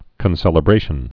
(kən-sĕlə-brāshən)